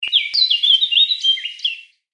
دانلود صدای جنگل 4 از ساعد نیوز با لینک مستقیم و کیفیت بالا
جلوه های صوتی